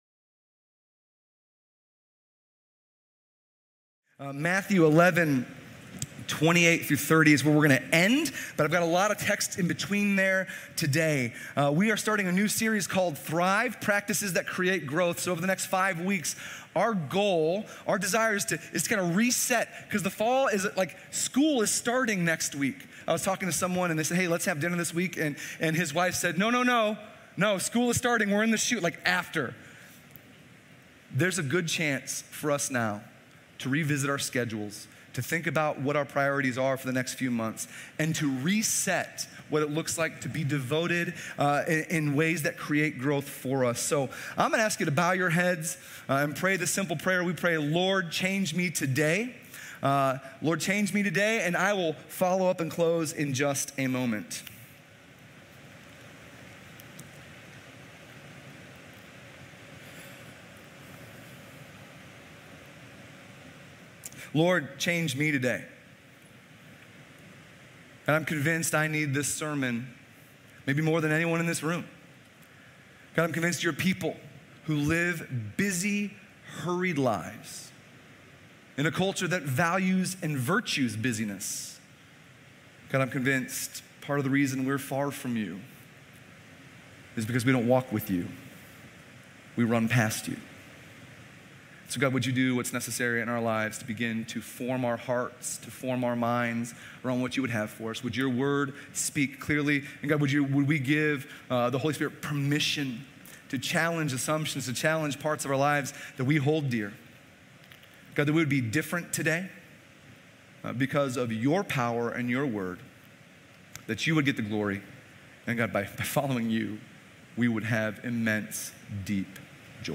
Sermon Notes How do we grow in our faith? What practices can we engage in right now that will draw us closer to God, and create deep flourishing in our souls?